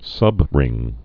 (sŭbrĭng)